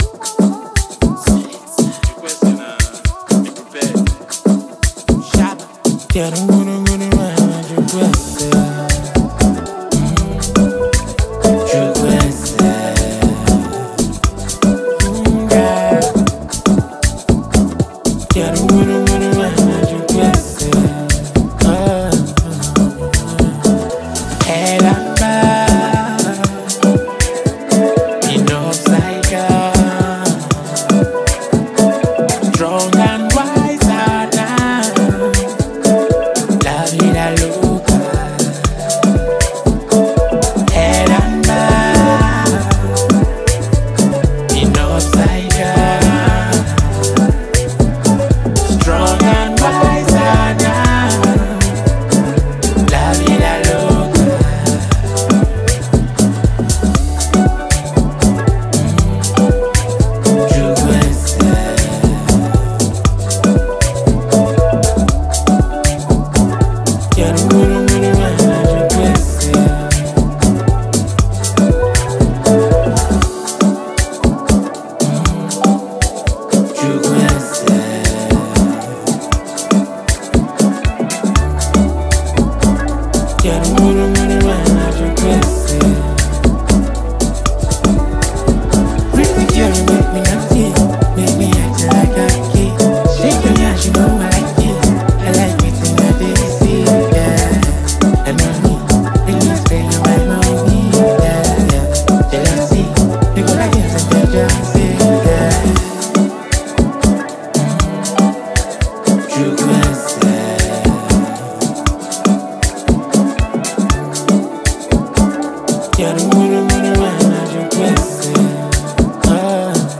a song that blends Afrobeat rhythm with heartfelt lyrics.
smooth vocals